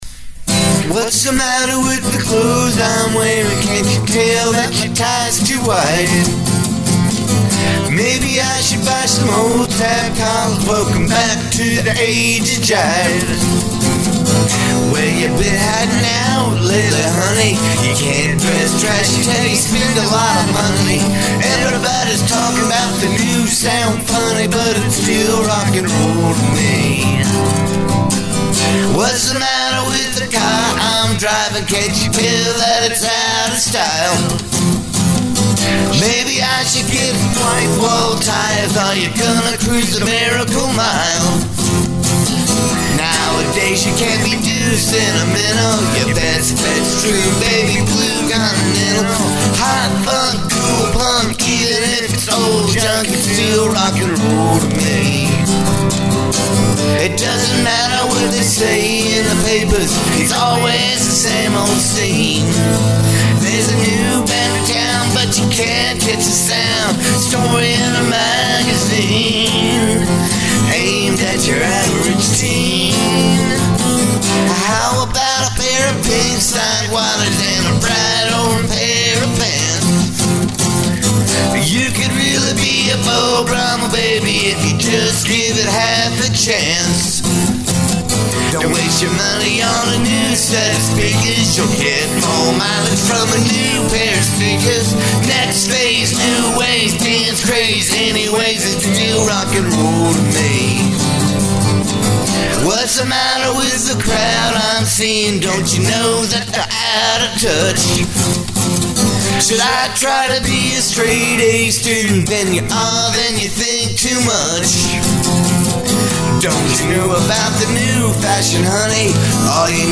Mouth full of words here, sheez!!